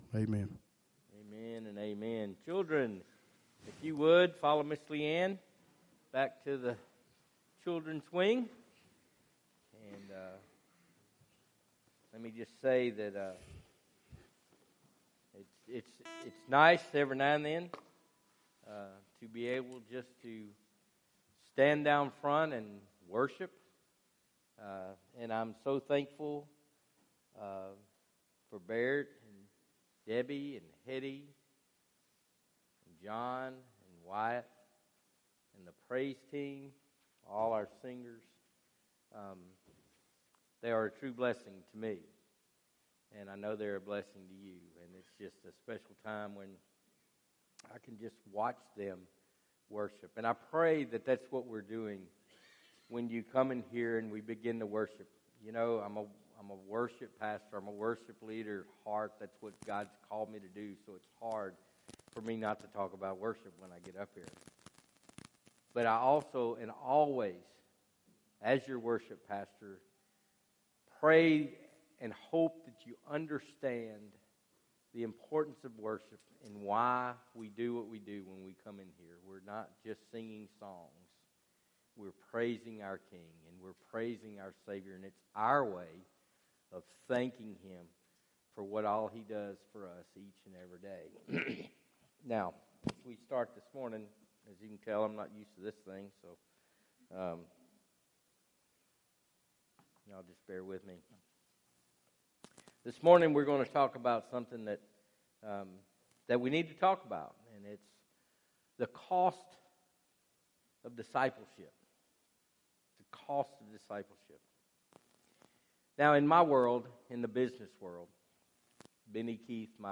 Sermon Audio «